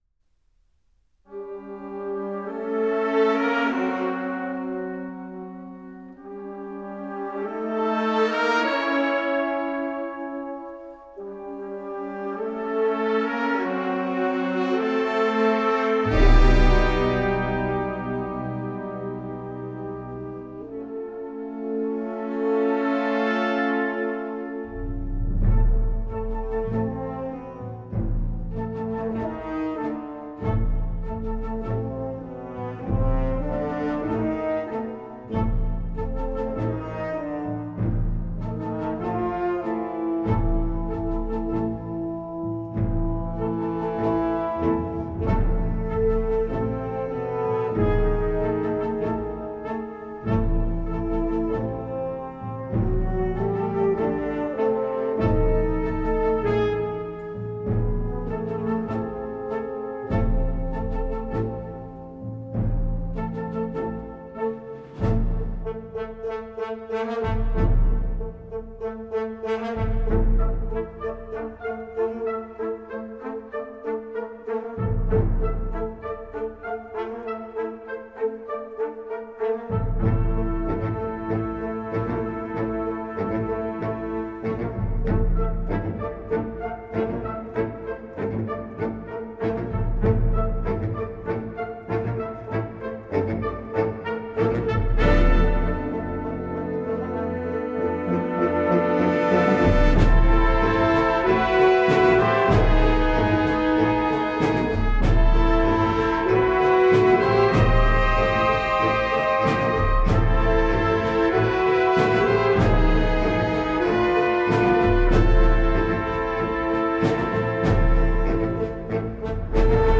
4:01 Minuten Besetzung: Blasorchester PDF